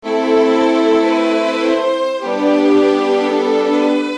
Hiphopstrings 1
描述：字符串短语
Tag: 100 bpm Hip Hop Loops Strings Loops 716.11 KB wav Key : Unknown